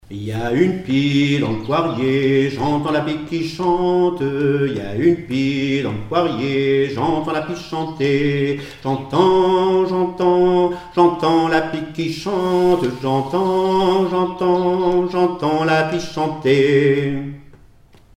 Mémoires et Patrimoines vivants - RaddO est une base de données d'archives iconographiques et sonores.
Divertissements d'adultes - Couplets à danser
enfantine : berceuse
Comptines et formulettes enfantines
Pièce musicale inédite